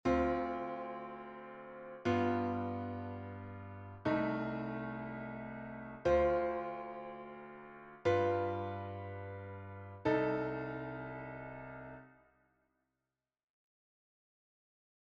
Improvisation Piano Jazz
Fondamentale + 3-7